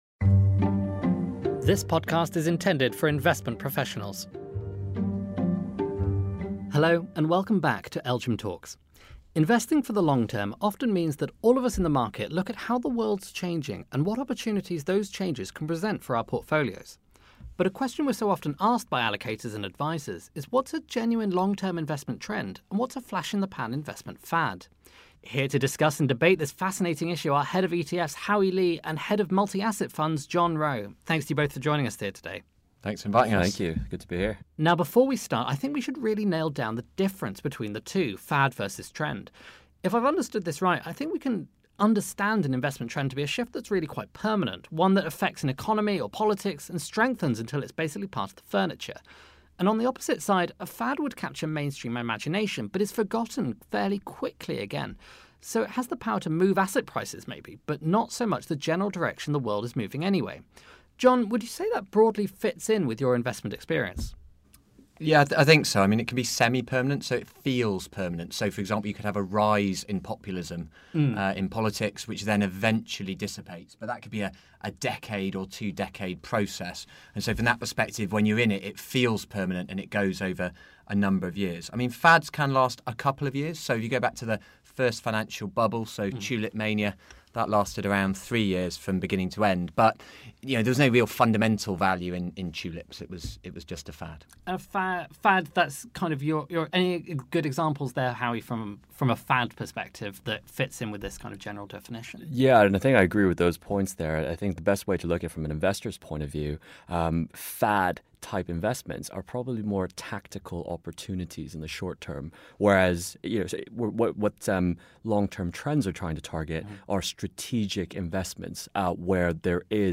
But a question we’re so often asked by allocators and advisers is what’s a genuine long-term investment trend, and what’s a flash-in-the-pan investment fad? In this conversation, we put together a method investors can use to differentiate the two and ask our guest experts their thoughts on some very esoteric topics, from vaping and cryptocurrencies to electric vehicles and e-sports.